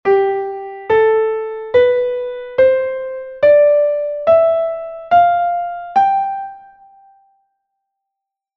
Modos eclesiásticos ou gregorianos
auténtico, finalis sol, repercusio re